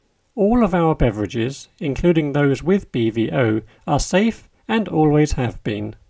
DICTATION 4